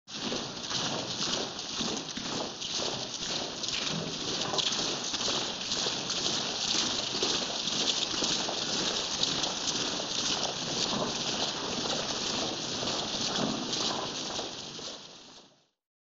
Солдаты шагают — второй вариант